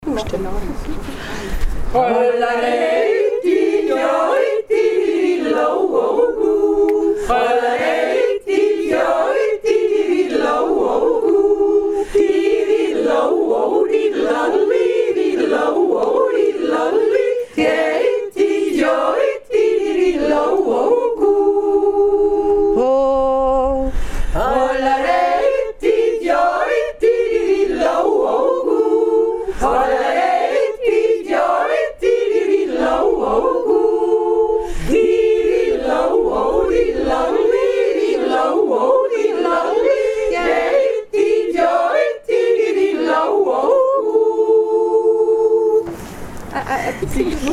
So jodelten wir auf den Rimpf 2016 - aufgeaht`s